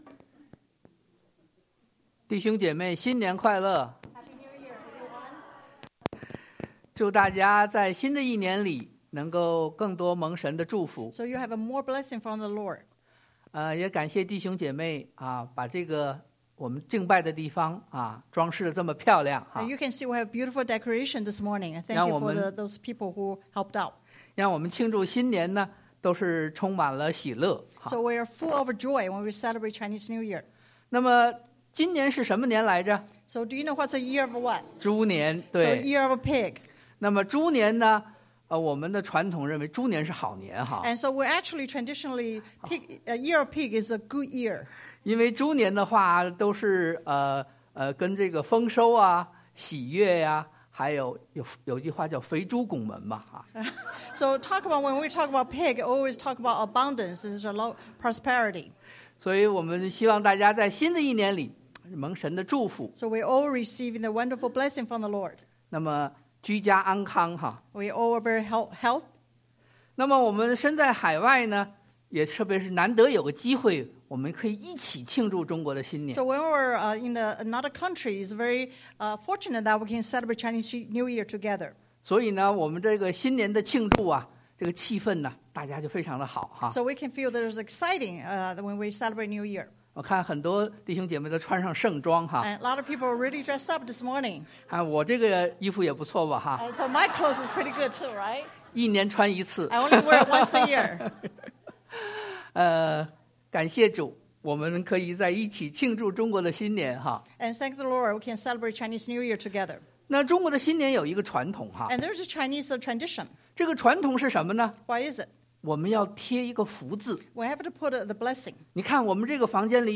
Mat 5:3-10 Service Type: Sunday AM Bible Text